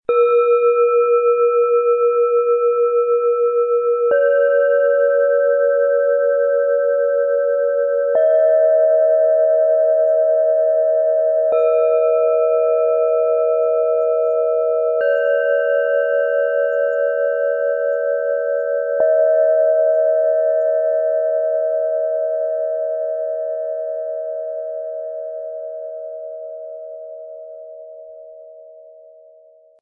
Innere Ruhe, stille Kraft und sanfte Wandlung spüren - Set aus 3 Planetenschalen in schwarz-gold, Ø 10,5 -12,4 cm, 1,11 kg
Hochfrequent & klärend
Im Sound-Player - Jetzt reinhören hören Sie den Originalton genau dieser drei Schalen.
Tiefster Ton: Mond
Mittlerer Ton: Pluto
Höchster Ton: Wasserstoffgamma
MaterialBronze